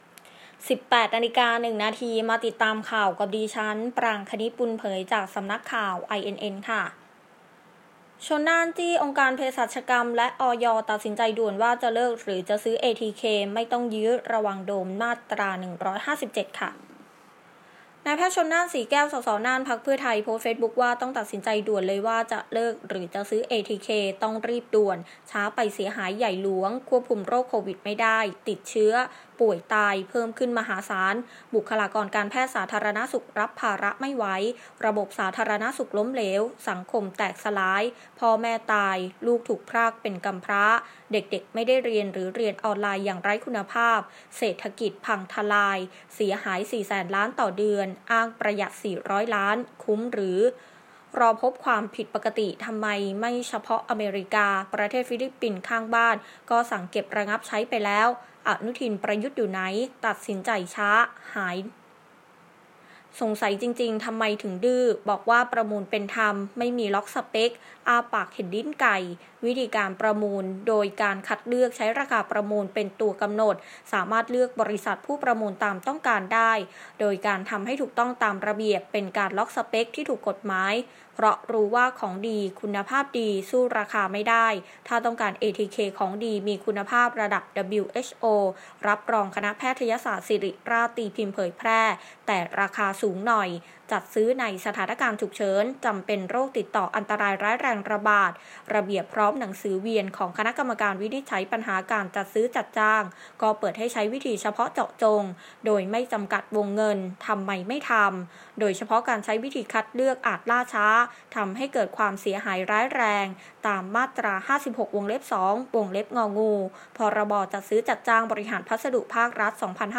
ข่าวต้นชั่วโมง 18.00 น.